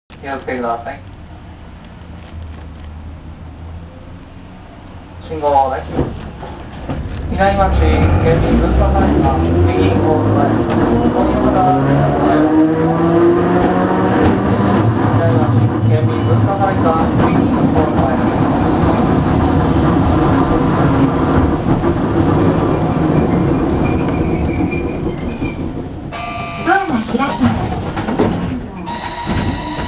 ・50形走行音
【城南線】道後公園→南町A（29秒：164KB）…61号にて
一応前・中・後期で分けたのですが基本的に音は同じで、全て吊り掛け式。個人的には、数ある路面電車の中でもかなり派手な音を出す部類に感じました。